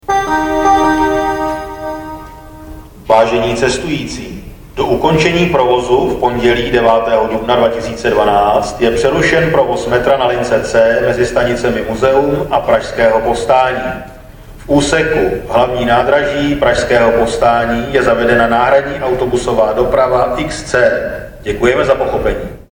Důsledně se dopravní podnik soustředil také na informace poskytované akusticky.
- Staniční hlášení o probíhající výluce metra si